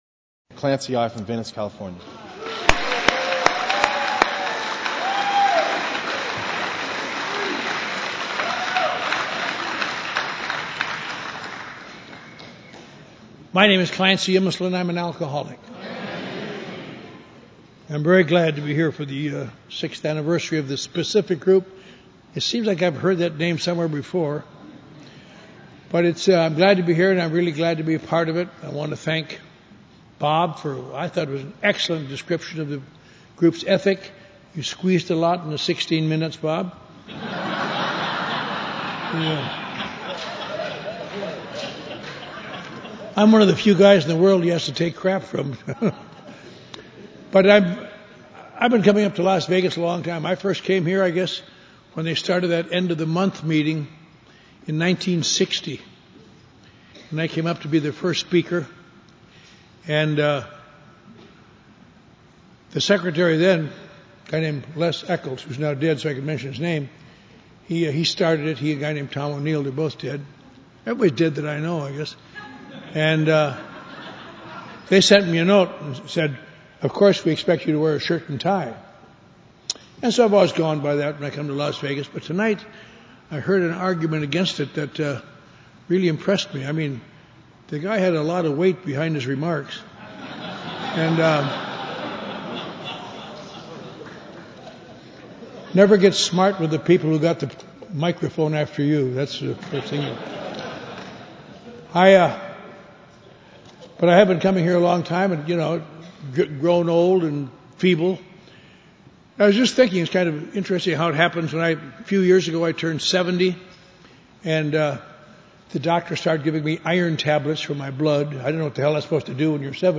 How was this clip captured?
Thursday Night Speaker Meeting Las Vegas NV